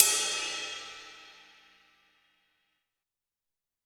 • Huge Cymbal Sound G Key 07.wav
Royality free single cymbal hit tuned to the G note. Loudest frequency: 7020Hz
huge-cymbal-sound-g-key-07-BFU.wav